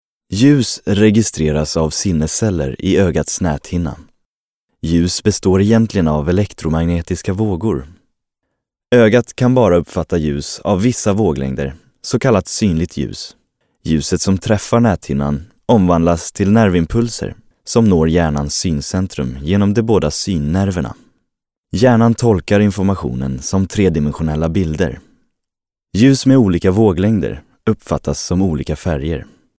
E-learning